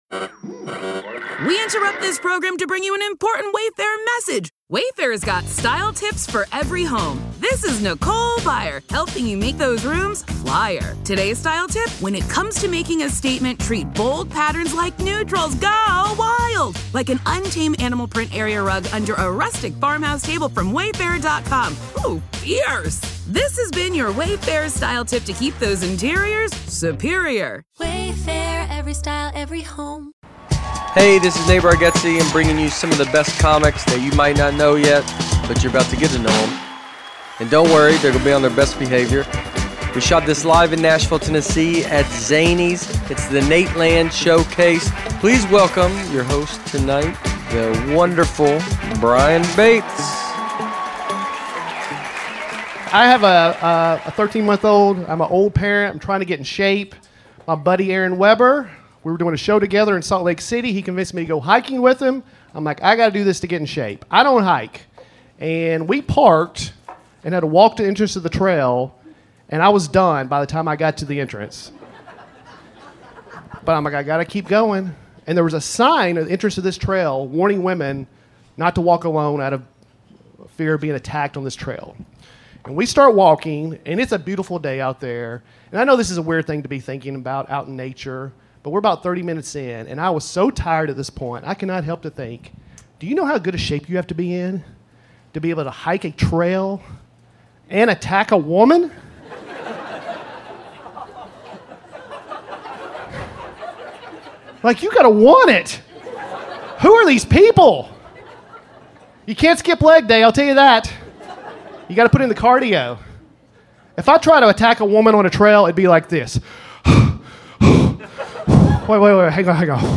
The Showcase features several of the best comics that you might not know yet, but you're about to get to to know 'em. Hosted by the members of The Nateland Podcast and directed by Nate Bargatze himself, The Showcase was recorded live at Zanies Comedy Club in Nashville, Tennessee. Standup comedy at its best.